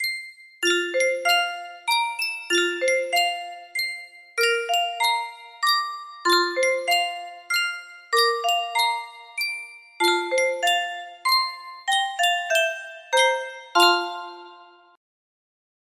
Sankyo Spieluhr - Viel Gluck und Viel Segen TMT music box melody
Full range 60